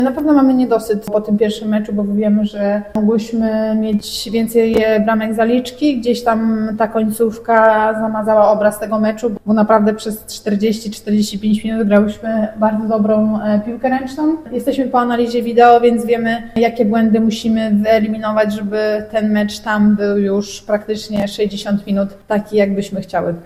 – Na pewno po pierwszym meczu mamy niedosyt – mówi w rozmowie z Radiem Lublin.